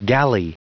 Prononciation du mot gally en anglais (fichier audio)
Prononciation du mot : gally